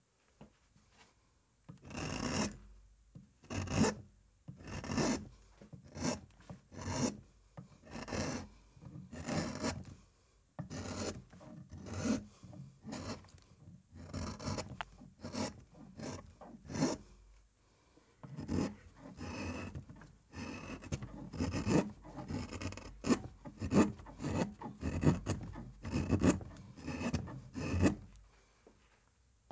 The sound of the roughing gouge cutting cross grain like that makes a mesmerizing sound. It’s something between a cat purring and a zipper slowly opening.
carving.mp3